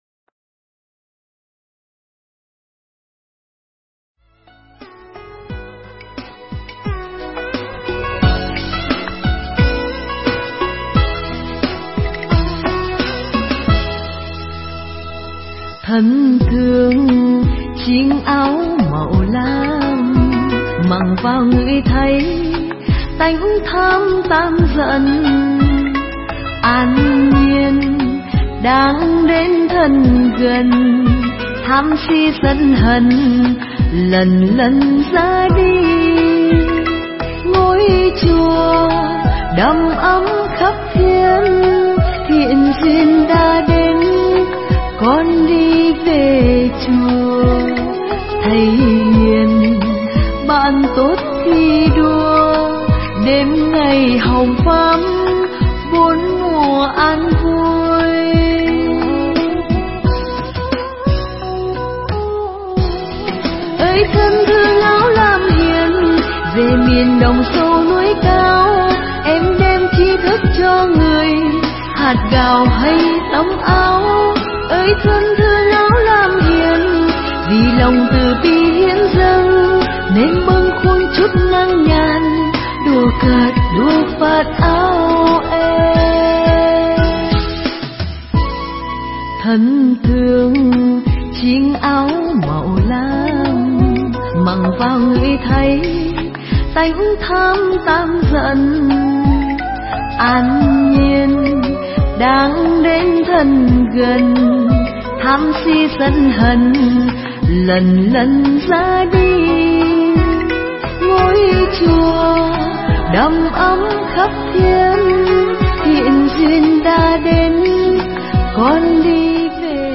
Thuyết pháp online